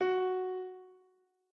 piano.ogg